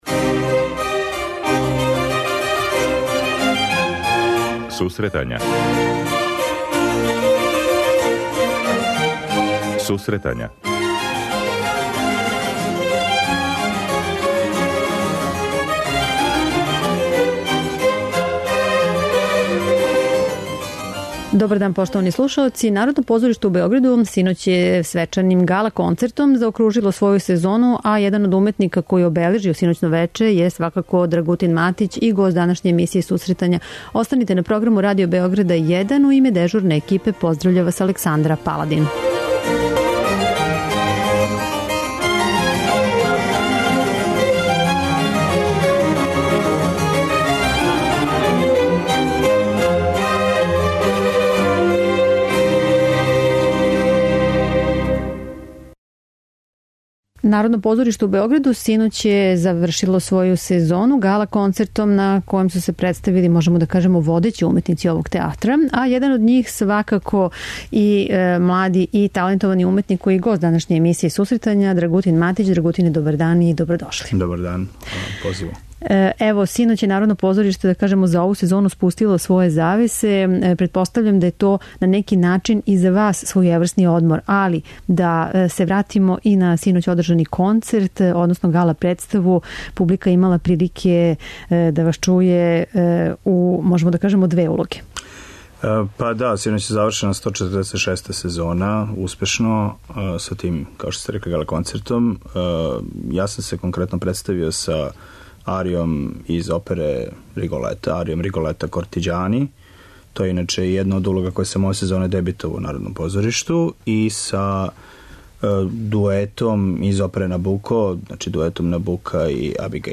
преузми : 26.09 MB Сусретања Autor: Музичка редакција Емисија за оне који воле уметничку музику.